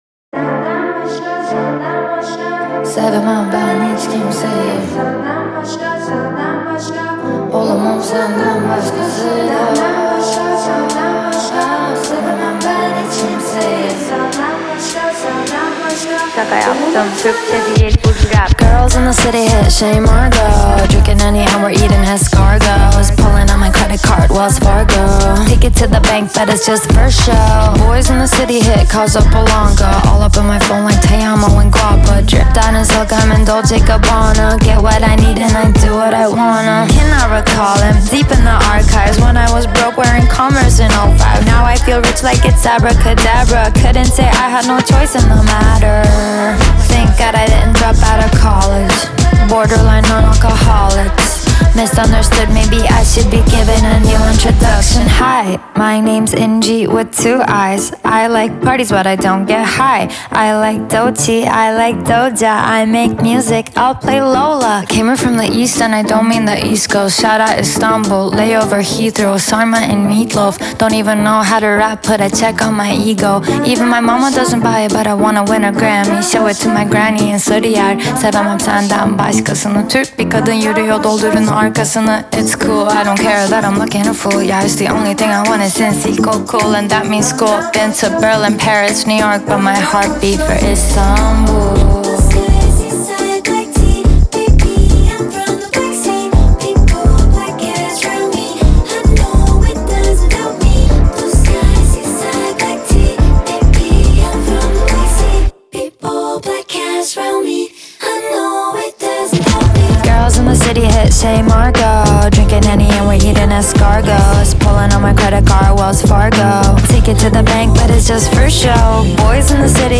BPM105-105
Audio QualityPerfect (High Quality)
Pop/Rap song for StepMania, ITGmania, Project Outfox
Full Length Song (not arcade length cut)